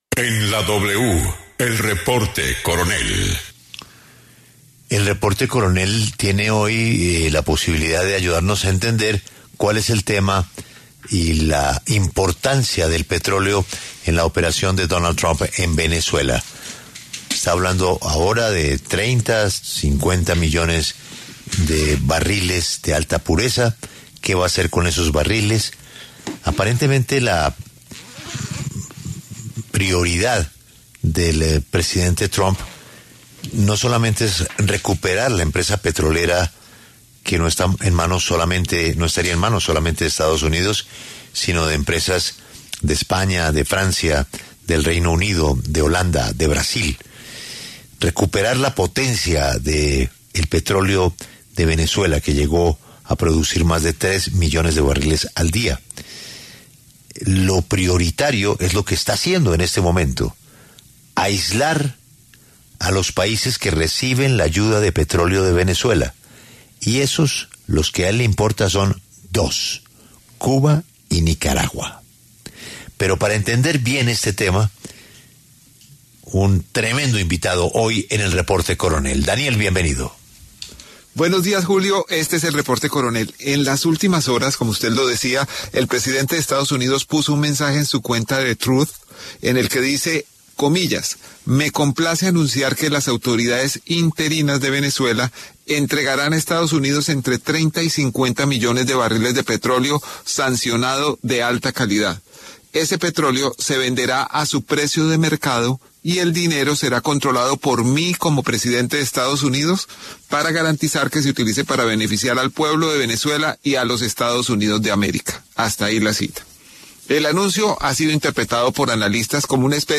Sobre la crisis de la industria y la apropiación por Estados Unidos habla Rafael Ramírez, el cuestionado zar del petróleo durante el gobierno de Hugo Chávez